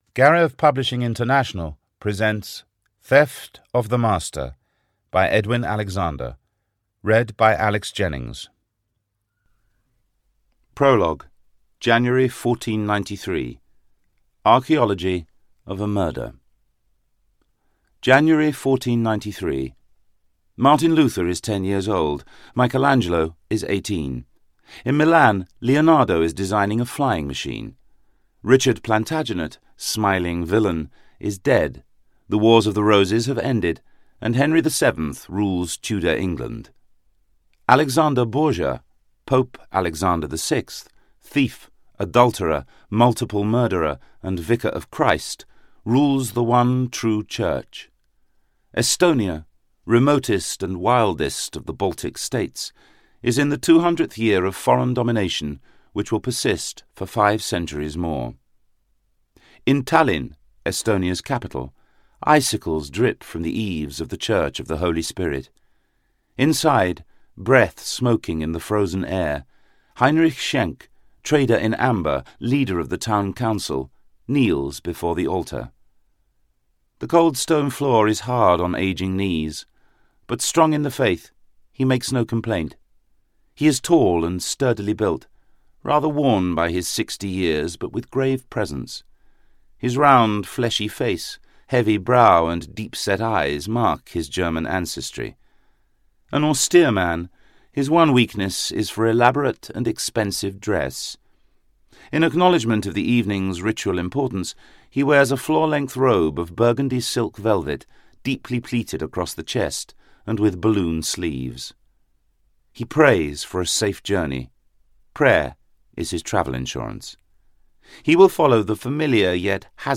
Audiobook Theft of the Master, written by Edwin Alexander.
Ukázka z knihy
• InterpretAlex Jennings